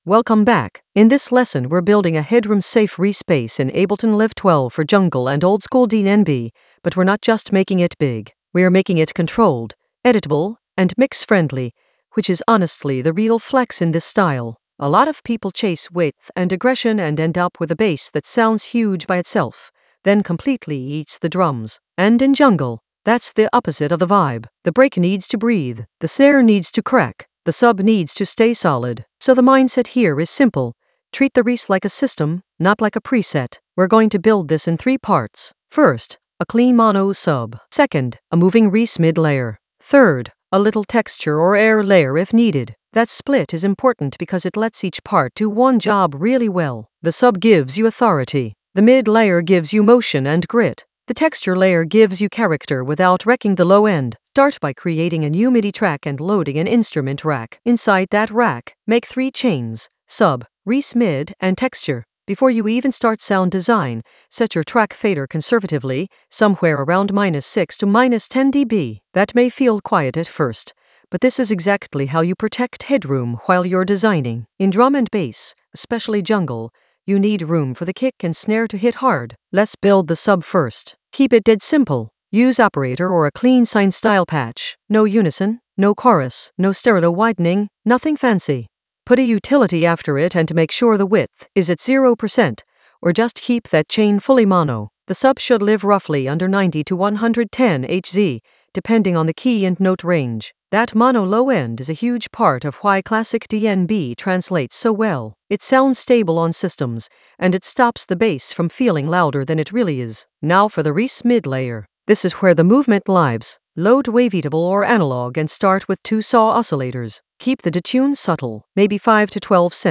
An AI-generated advanced Ableton lesson focused on Playbook for reese patch without losing headroom in Ableton Live 12 for jungle oldskool DnB vibes in the Edits area of drum and bass production.
Narrated lesson audio
The voice track includes the tutorial plus extra teacher commentary.